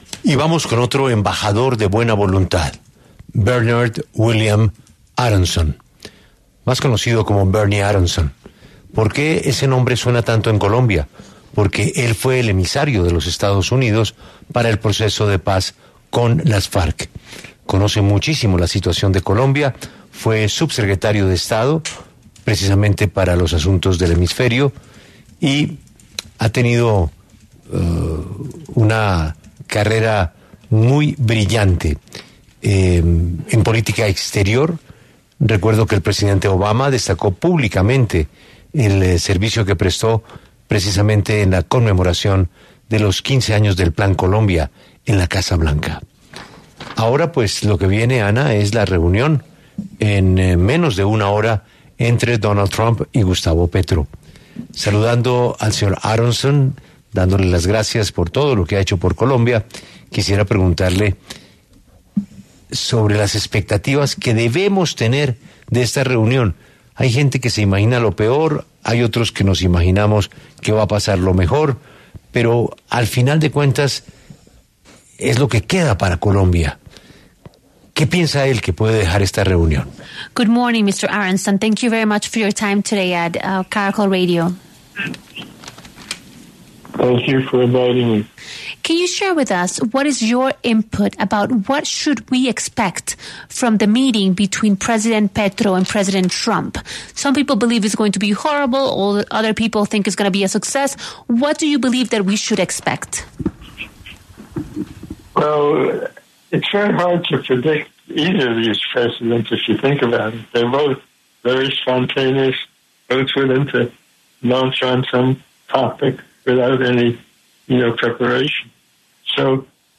Bernard William Aronson, diplomático y empresario estadounidense que se desempeñó como subsecretario de Estado de Estados Unidos para Asuntos Interamericanos entre 1989 y 1993, pasó por los micrófonos de 6AM W para hablar sobre la reunión Trump-Petro.